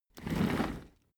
Royalty free sounds: Chair